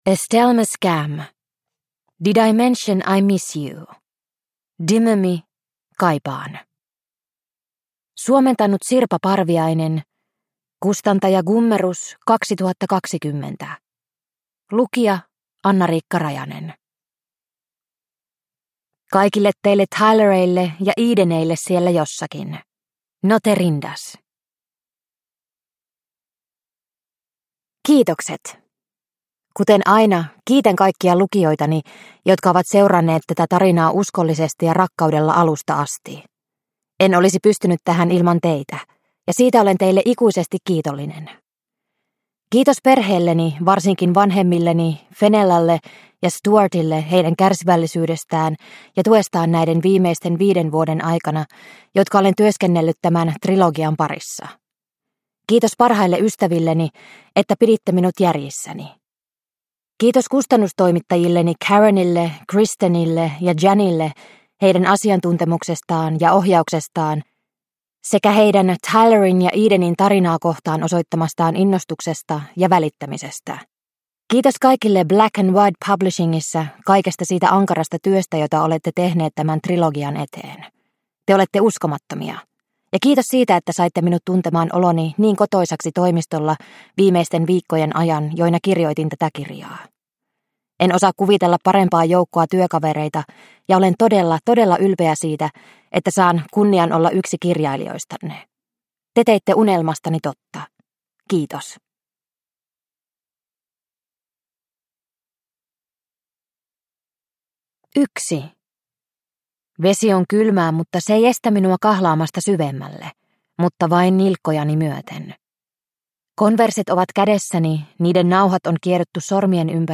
DIMIMY - Kaipaan – Ljudbok – Laddas ner